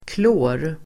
Uttal: [klå:r]